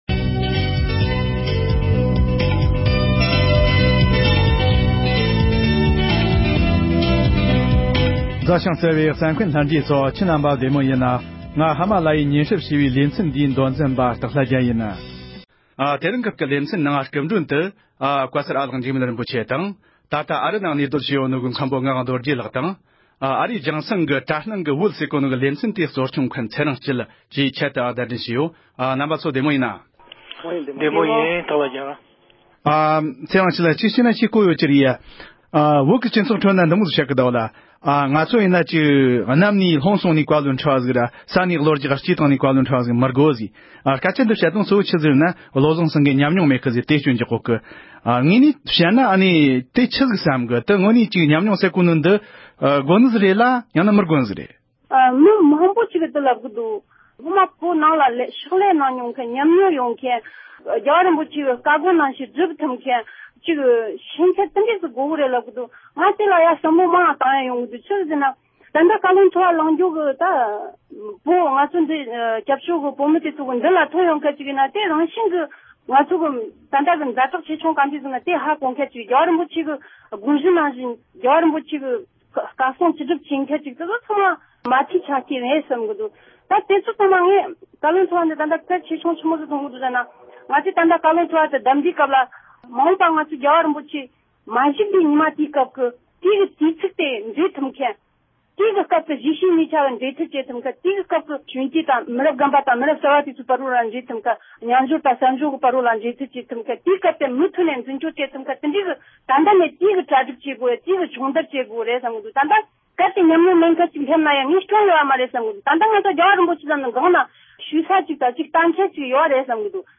བོད་མི་གསུམ་དང་ལྷན་དུ་བགྲོ་གླེང་ཞུས་པར་གསན་རོགས༎